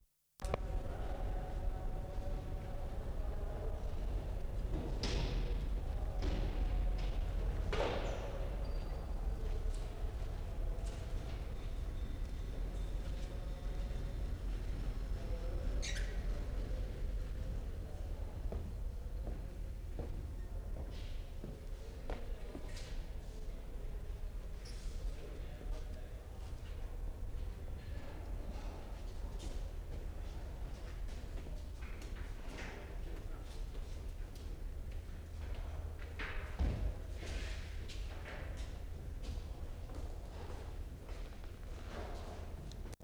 EATON'S AUCTION 0'40"
6. Heavy low frequency rumble. A strange opening -- sounds like distant singing in a very large cathedral, as recordists approach auction from a distance.